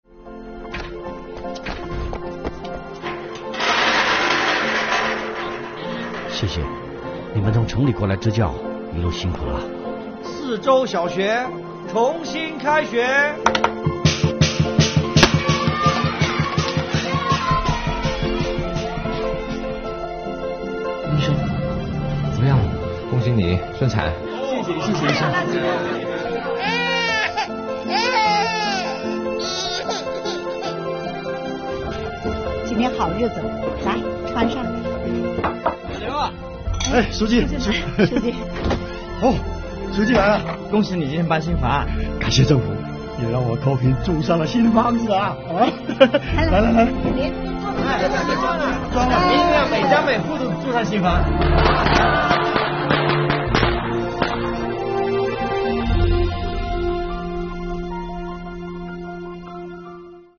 公益广告 | 上善若税，幸福之声
本片主要设计了三组幸福场景，分别是泗洲小学重新开学的幸福、产房门口年轻爸爸听到孩子顺产降临人间消息的幸福、老刘一家搬进了新房的幸福。通过不同人群的幸福之声，反映出我国财政在教育、医疗卫生与健康、扶贫等民生领域的支出，体现我国税收“取之于民、用之于民”的原则，展现税收为国计民生发挥着重要的保障作用。